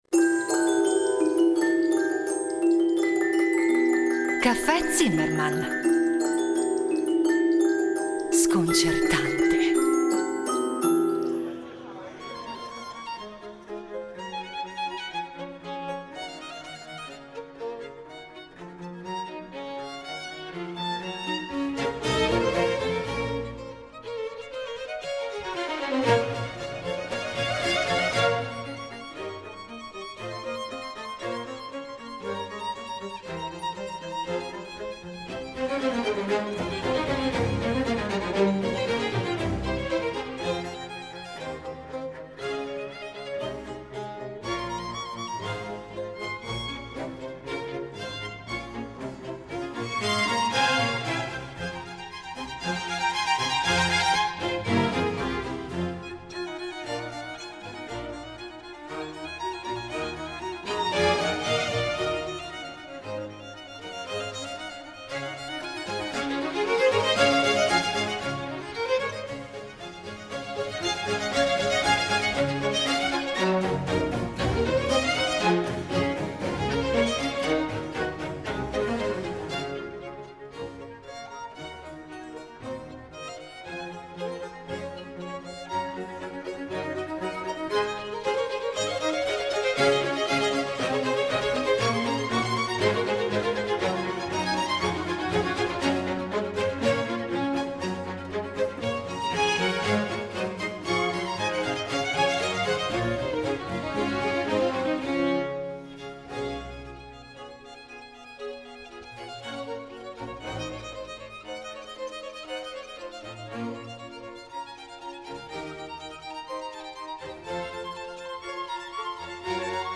A dirigere Il Giardino Armonico dal 1989 Giovanni Antonini , tra i membri fondatori, che ai microfoni di Rete Due ne ripercorre la storia.
A guidarci le musiche della rilevante quanto varia discografia dell’ensemble (proposta in ordine cronologico).